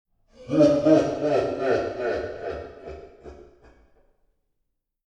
Click to hear evil laugh ...
evil_laugh.mp3